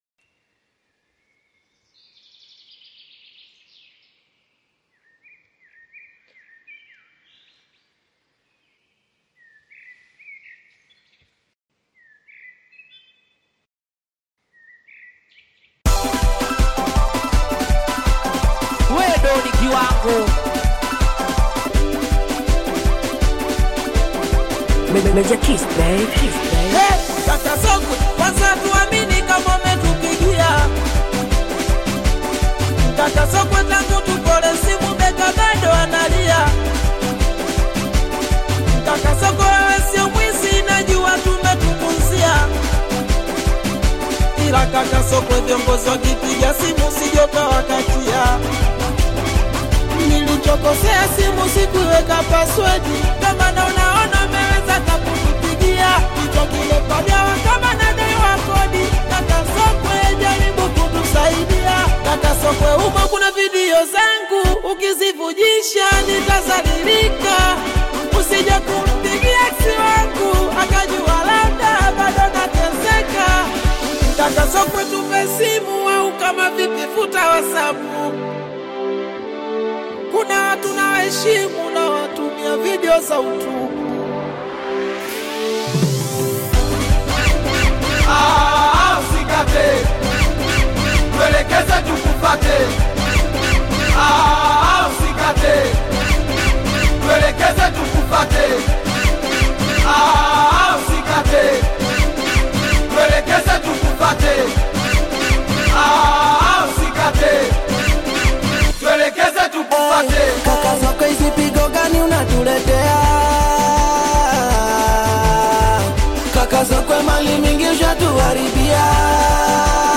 AUDIOSINGELI